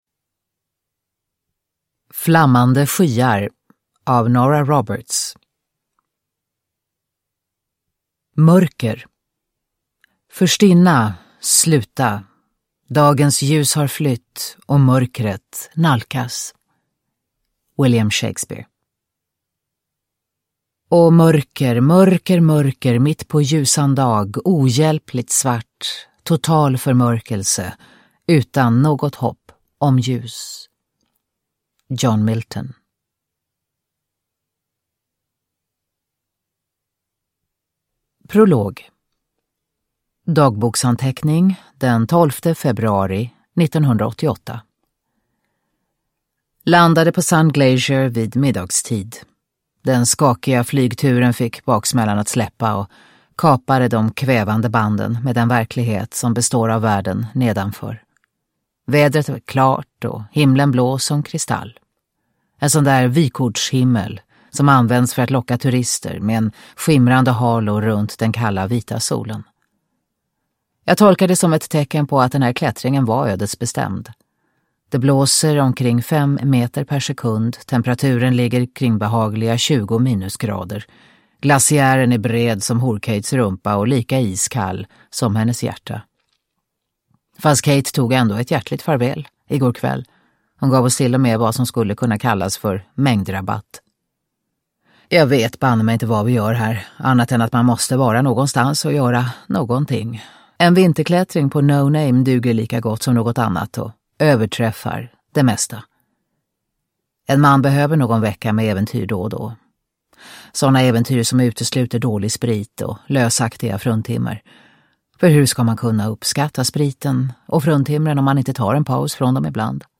Produkttyp: Digitala böcker
Uppläsare: Katarina Ewerlöf